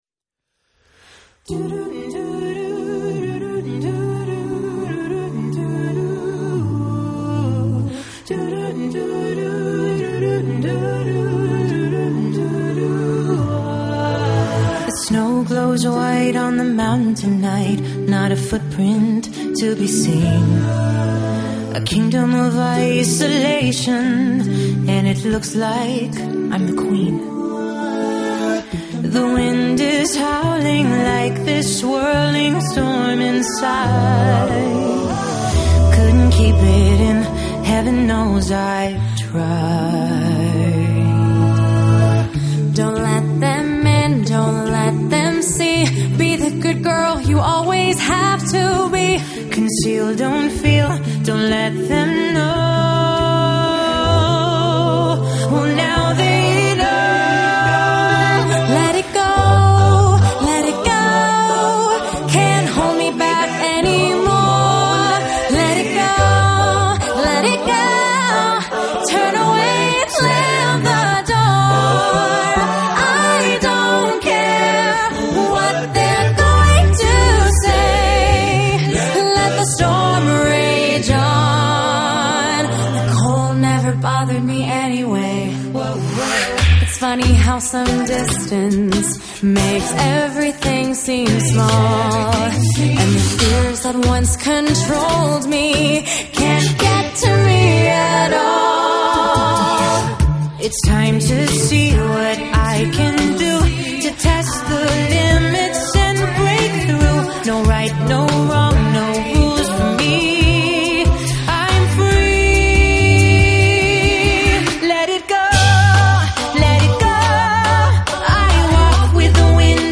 將耳熟能詳的耶誕歌曲，全數以A cappella的方式演繹，又加入福音祥和氣氛，一股暖意湧上心頭。